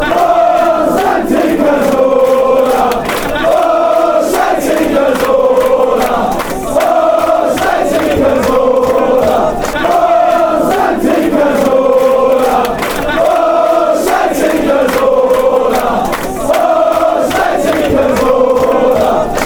این سرود تو اشبرتون خونده میشه موقعی که سانتی پا ب توپ میشه.یا گل میزنه تو ورزشگاه خونده میشه.
ohh-santi-cazorla-fanchants-free.mp3